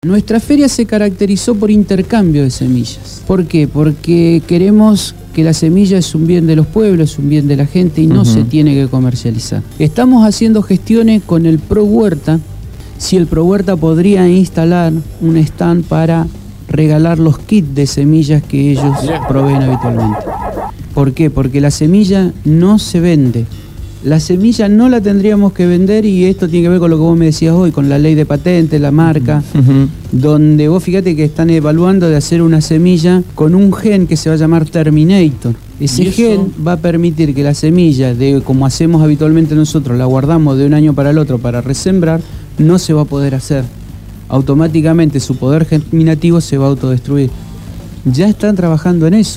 estuvo en el estudio de la Gráfica.
Entrevistado